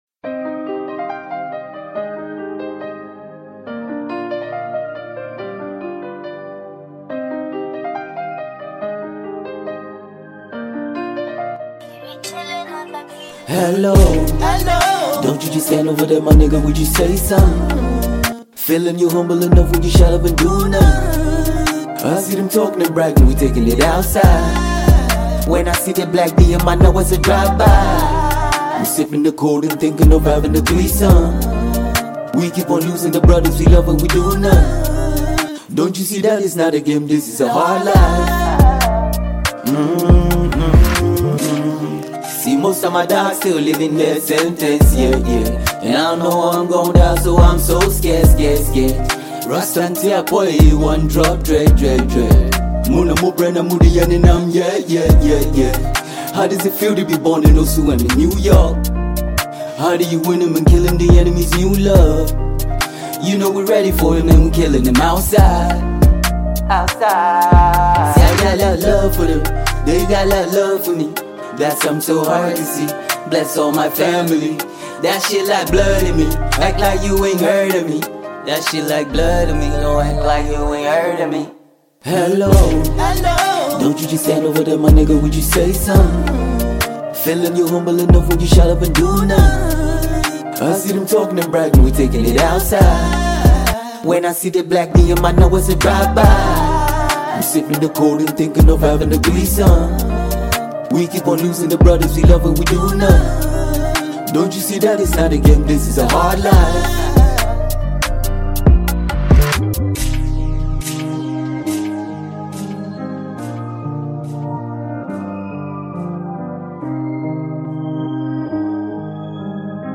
Ghanaian hiphop artist